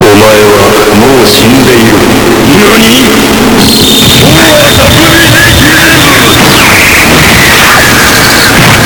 Play, download and share NaNi!!!?!Extra bass original sound button!!!!
nani-extra-bass.mp3